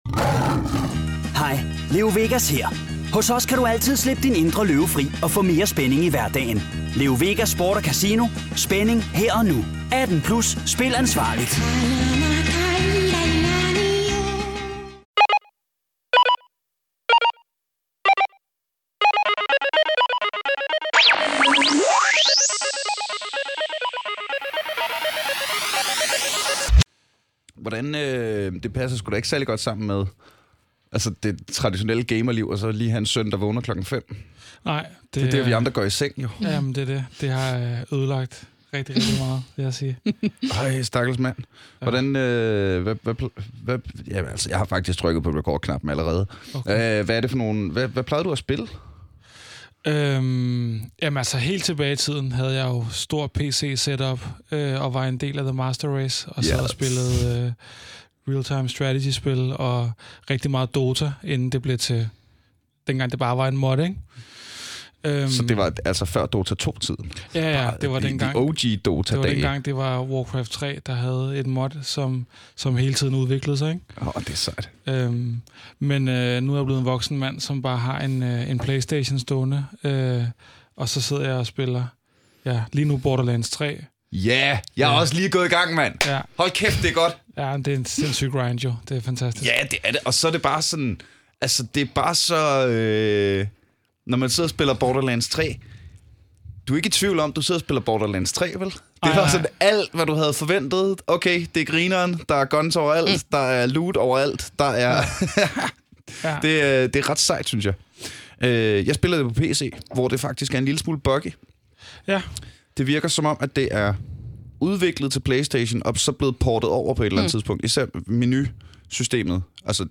På ITU har vi et af verdens bedste (og første) forsknings-centre for computerspil, og jeg har 2 flinke brainiacs i studiet for at snakke akademika, kønsnormativisme og Borderlands 3!